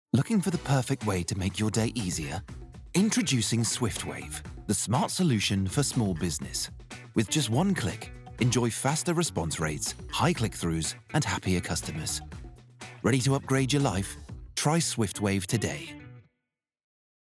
British Voice Over Artist
Utilising a professional home studio setup, I am able to provide not only fast deliverables, but clean and high quality audio.
Advert Demo